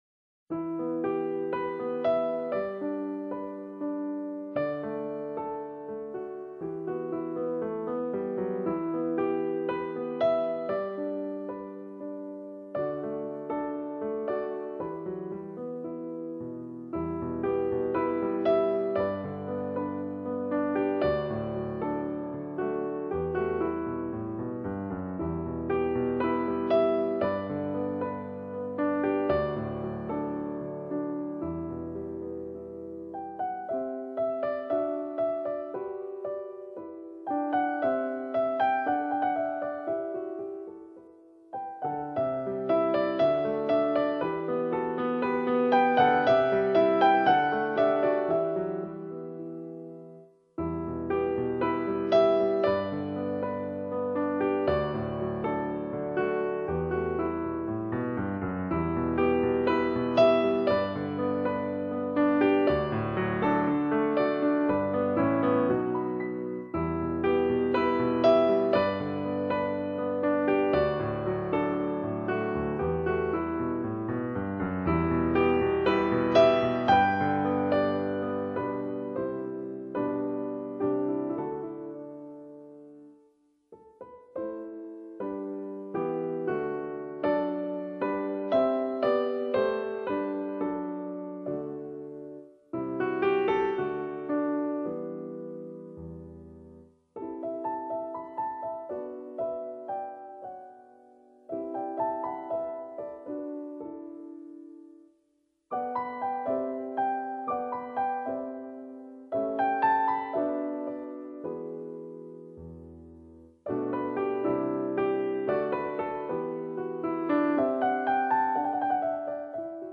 录音地点：中央音乐学院小演奏厅
最佳流行音乐演奏专辑奖